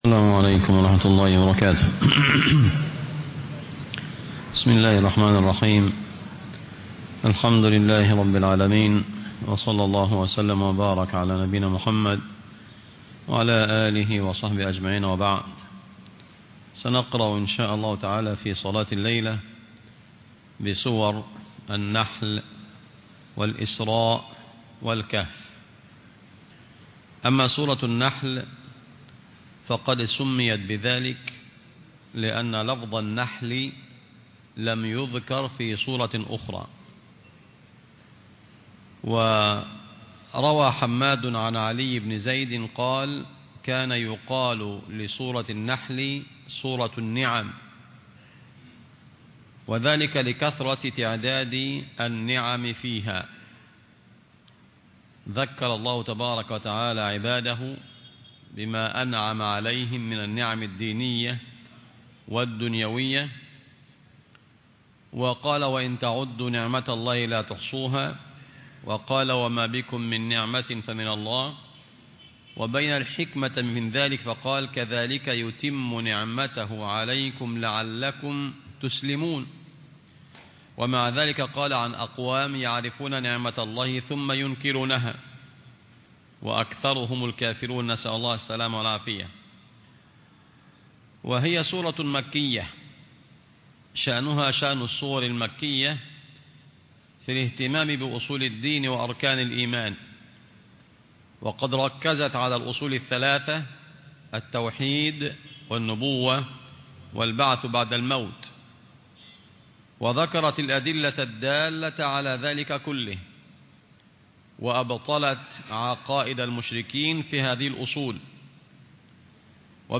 القرآن الكريم وعلومه     التفسير